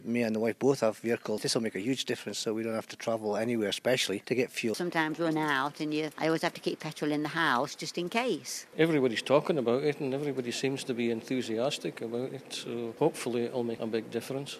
LISTEN: Newcastleton residents have their say on the opening of the first fuel pump in the village in a decade